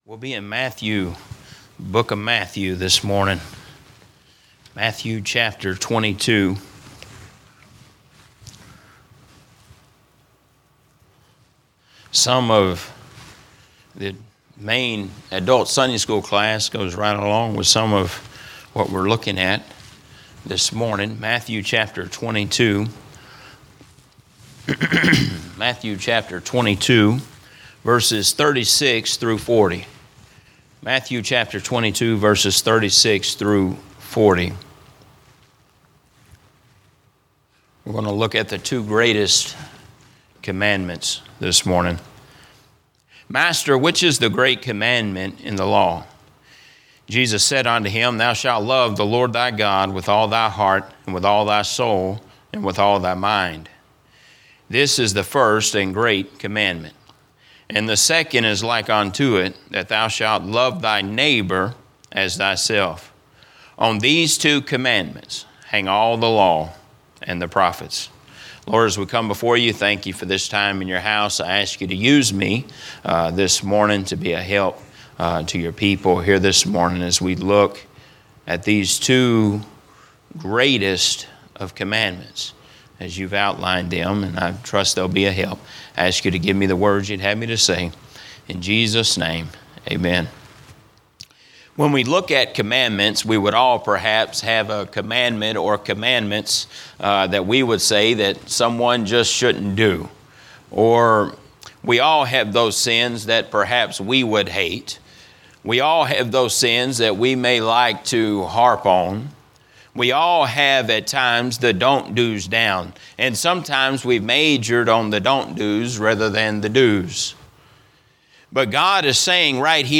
Sermons - Emmanuel Baptist Church